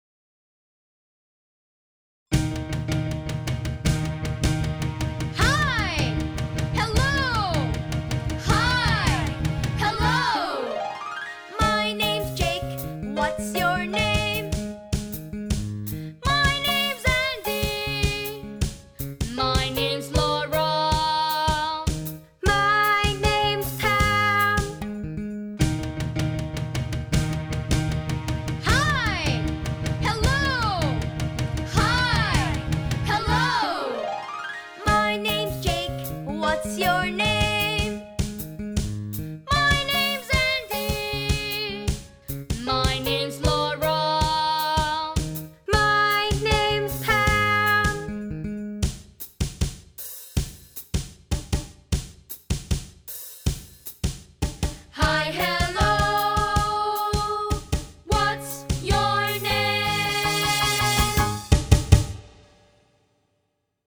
UNIT 1 SONG